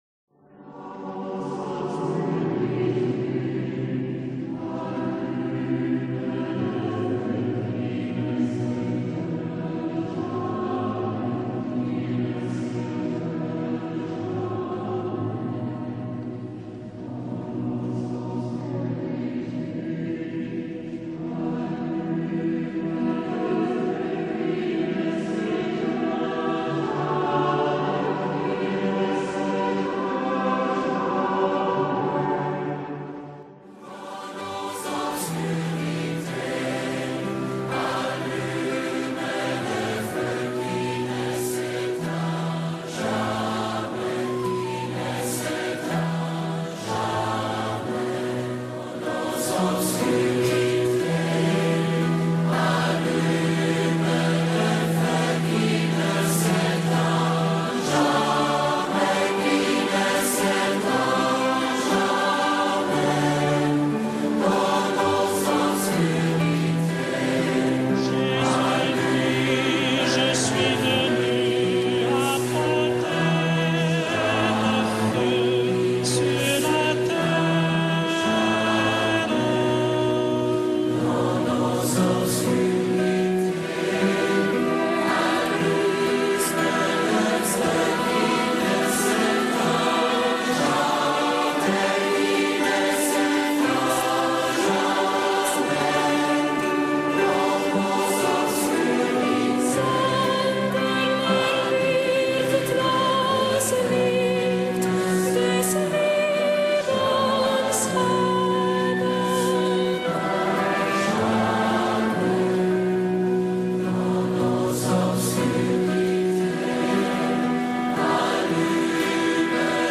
CANTIQUE DE TAIZÉ : DANS NOS OBSCURITÉS